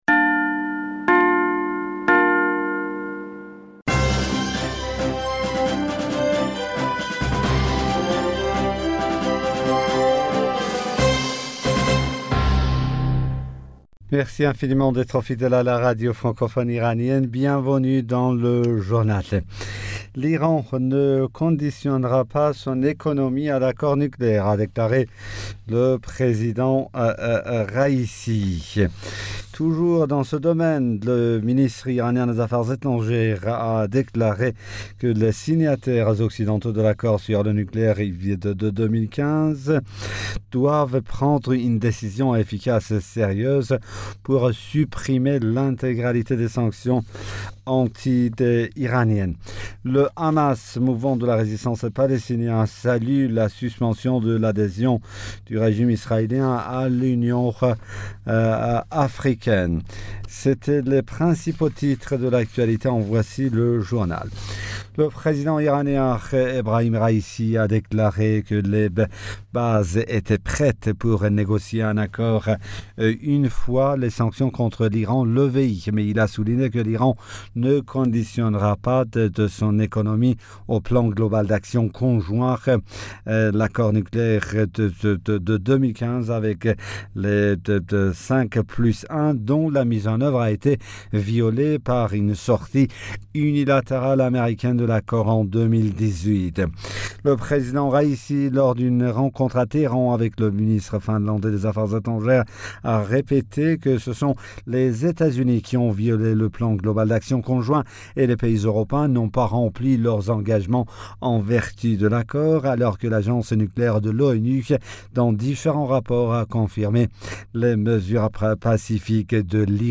Bulletin d'information Du 08 Fevrier 2022